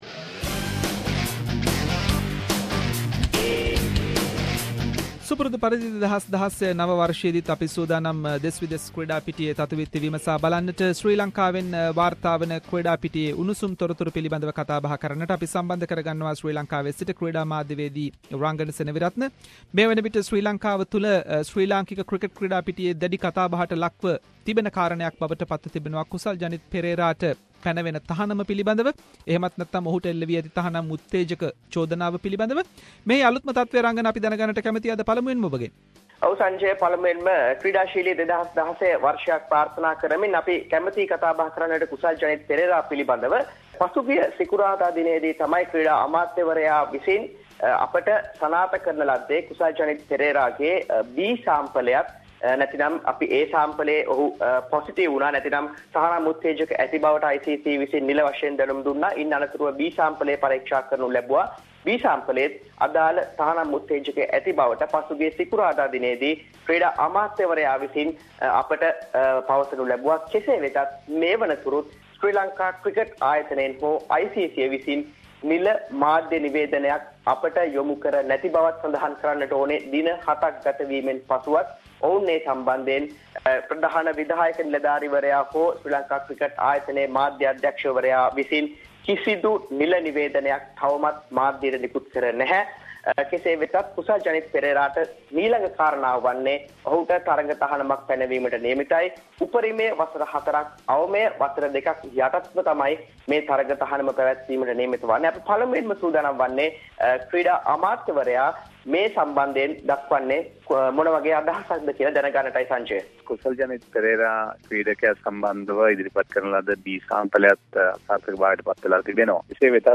In this weeks SBS Sinhalese sports wrap…. Kusal Janith Pereras latest situation, Latest from Sri Lanka cricket tour to New Zealand and many more local and international sports news. Sports journalist